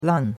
lan4.mp3